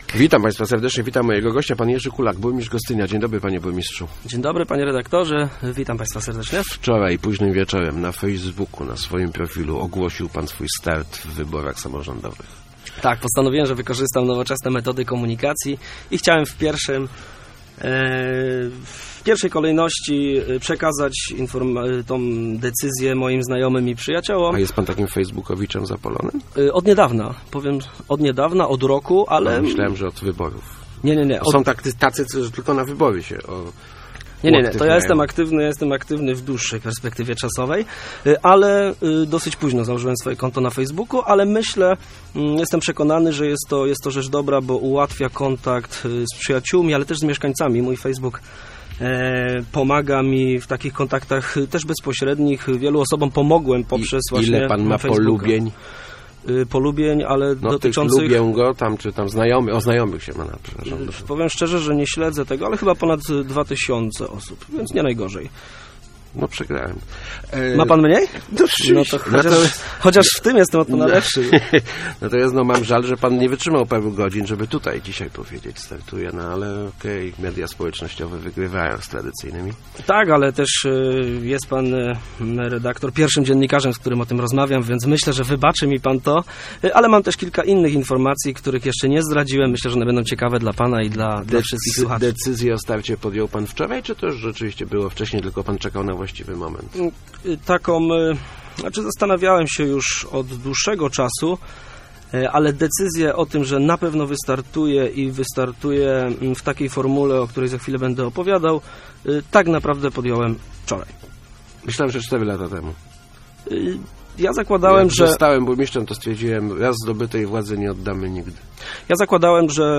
Będę starał się o reelekcję - powiedział w Radiu Elka burmistrz Gostynia Jerzy Kulak.